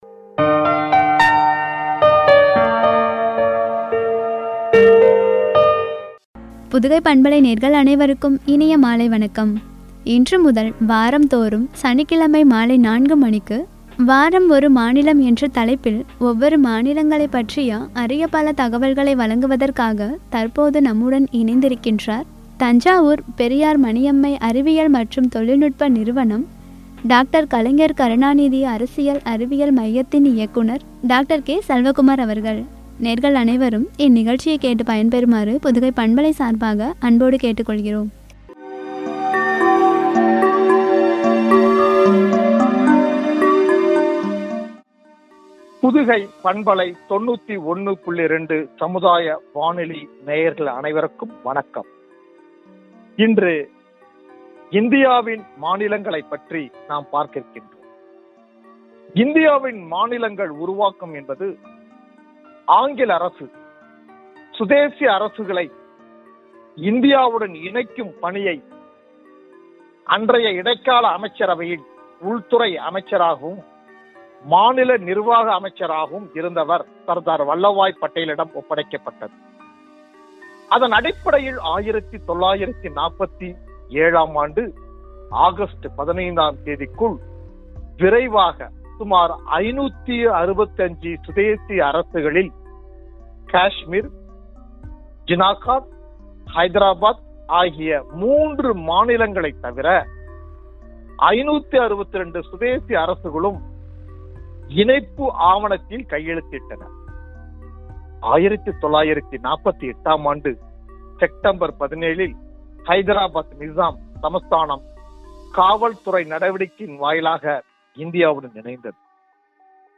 என்ற தலைப்பில் வழங்கிய உரை